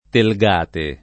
[ tel g# te ]